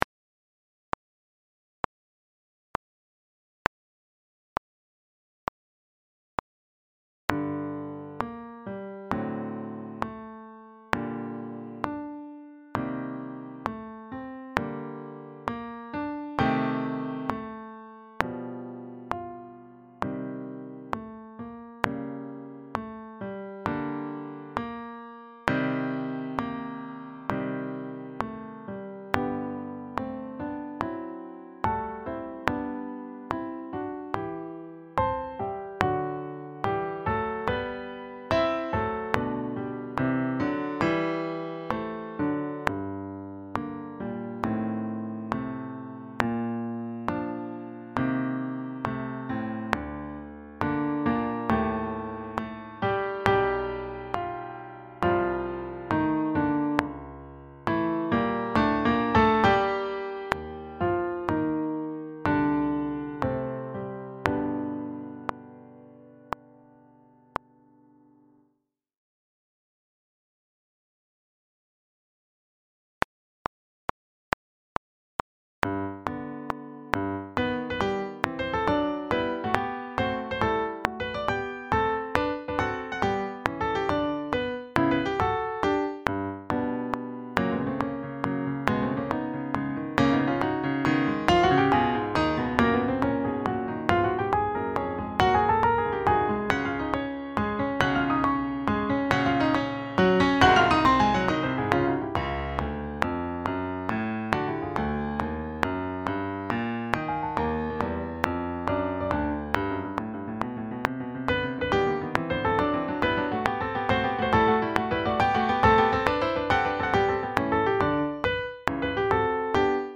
Sax Octets
sSAATTBbDuration:
Here we present a sax octet arrangement of the main theme and four of the simpler variations that still cover a good range of styles and moods of the full suite.
Backing track
131-4-enigma-backingtrack.mp3